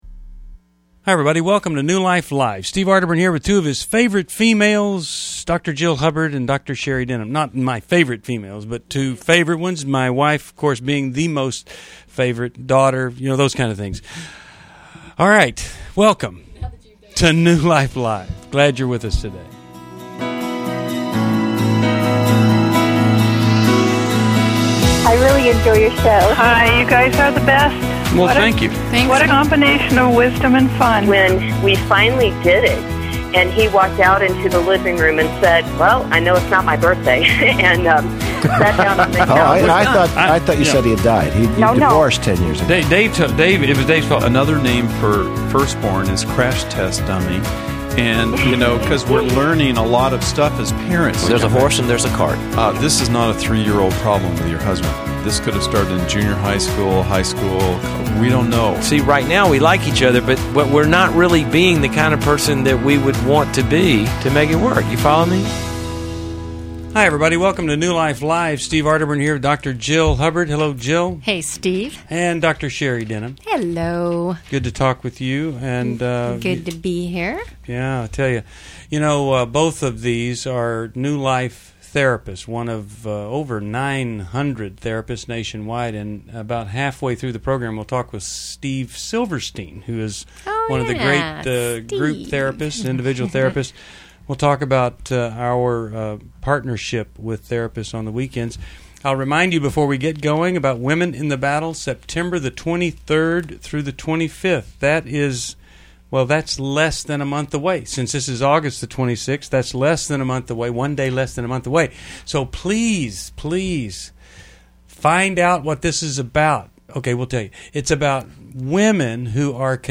Explore sexual integrity, love addiction, and family boundaries in this episode of New Life Live, featuring insightful caller questions and expert advice.